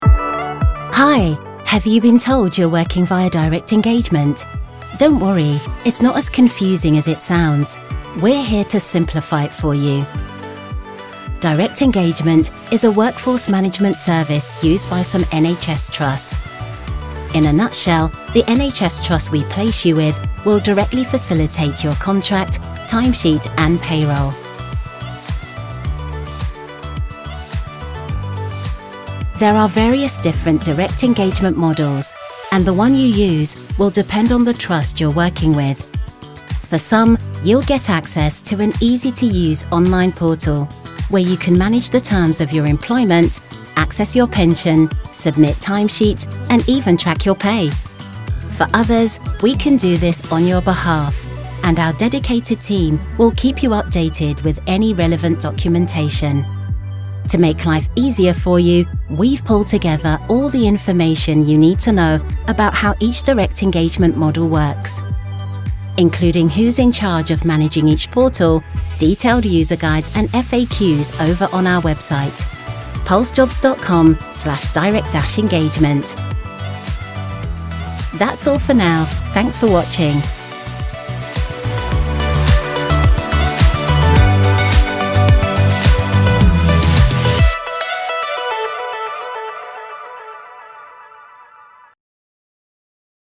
Engels (Brits)
Vriendelijk, Warm, Natuurlijk, Commercieel, Zakelijk
Explainer
Een Britse vrouwelijke stemactrice die een scala aan stijlen inspreekt voor zowel narratieve als commerciële doeleinden.